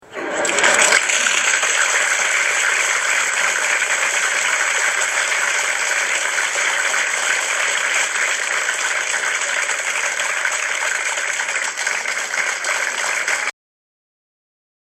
Ending Applause